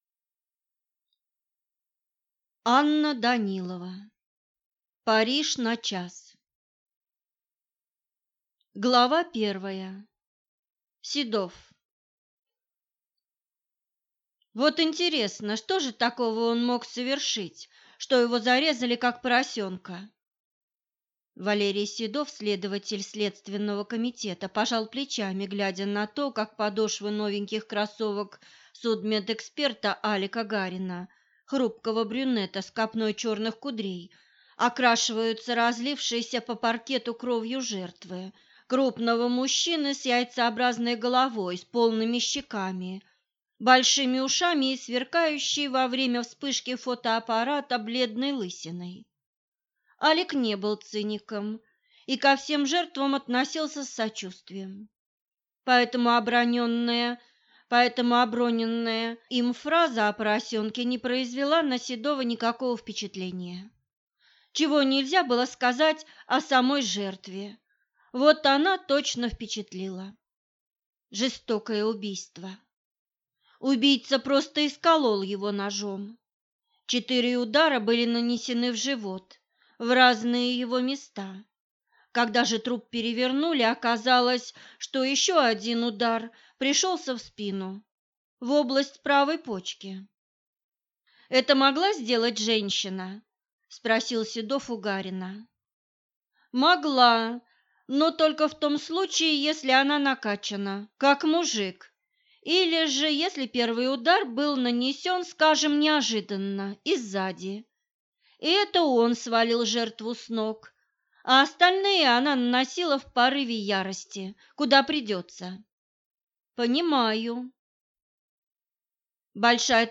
Аудиокнига Париж на час | Библиотека аудиокниг